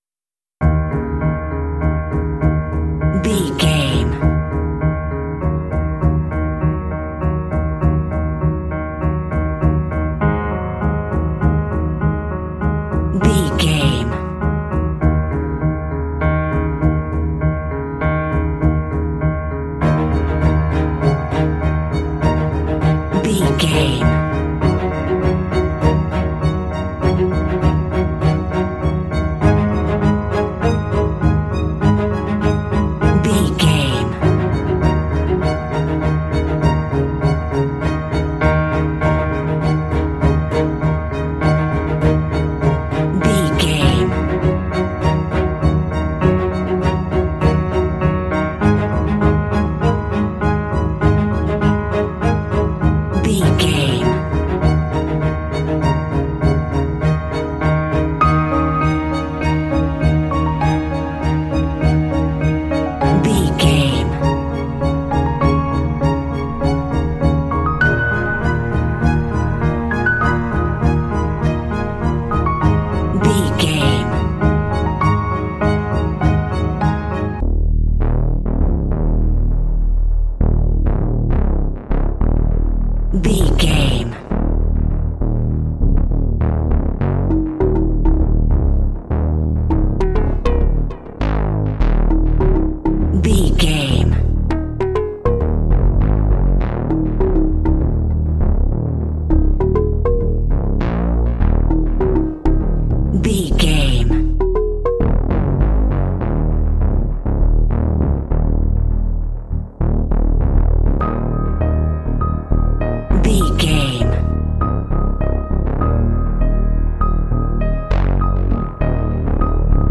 Aeolian/Minor
E♭
suspense
anxious
scary
piano
strings
synthesiser
cinematic